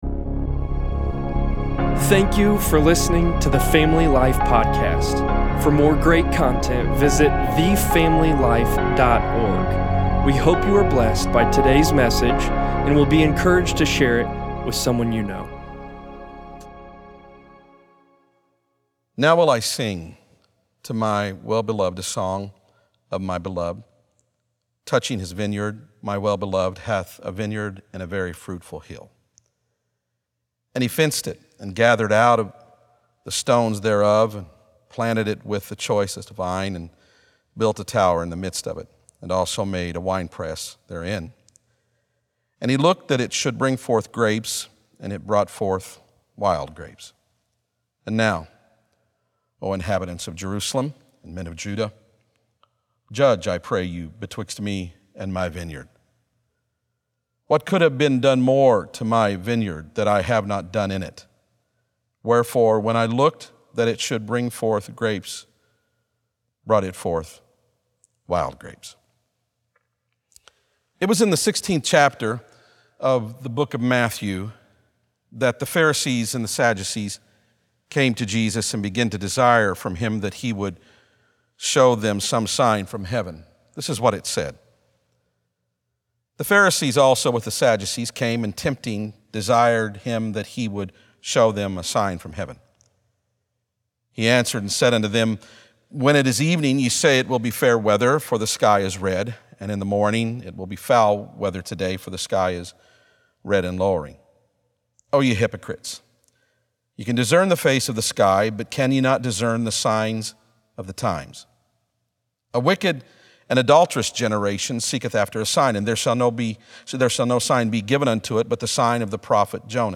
6.7.20sermon_p.mp3